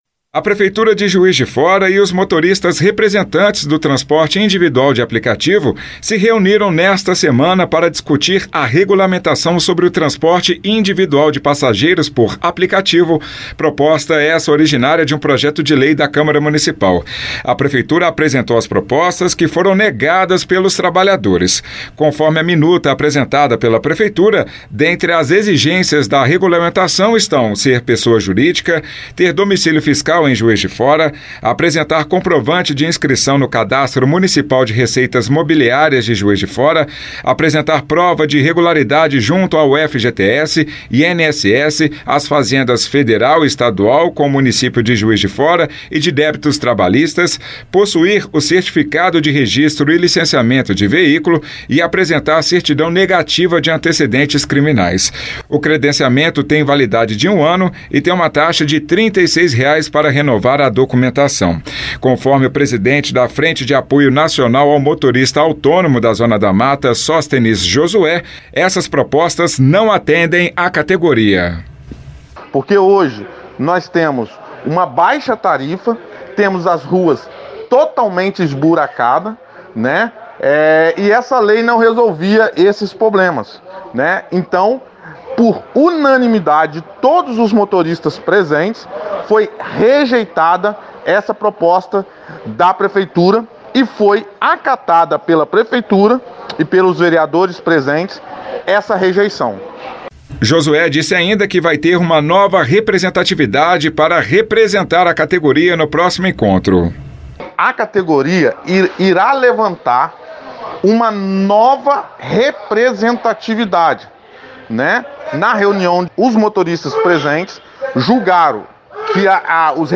regulamentacao-aplicativo-uber-jf-juiz-de-fora-lei.mp3